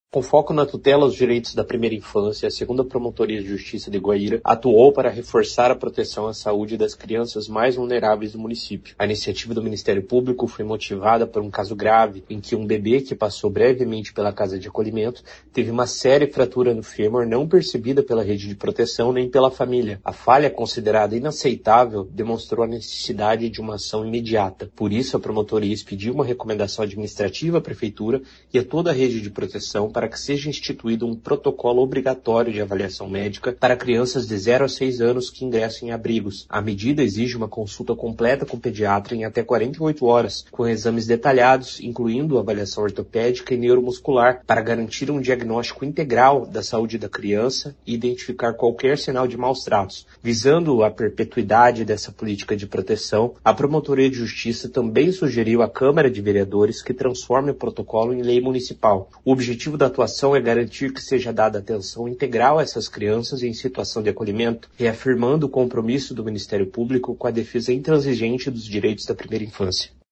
Ouça o que diz o promotor de Justiça, Renan Guilherme Goes de Lima: